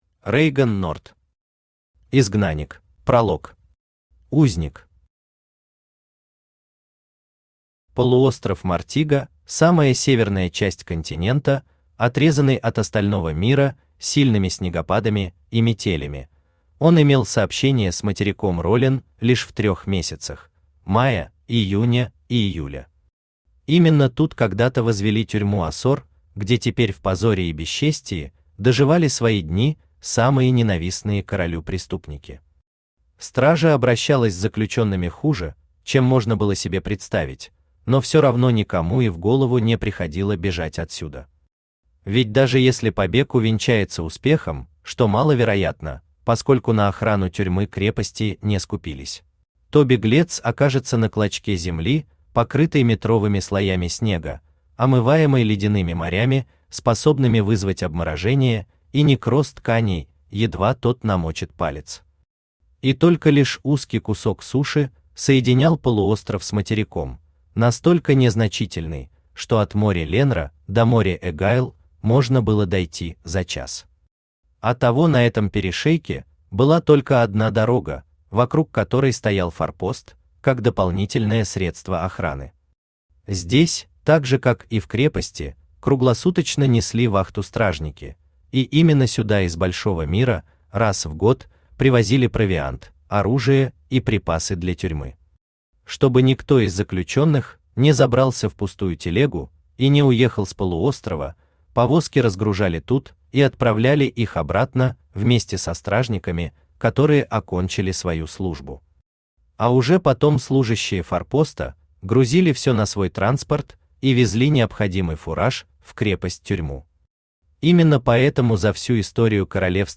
Aудиокнига Изгнанник Автор Reigon Nort Читает аудиокнигу Авточтец ЛитРес.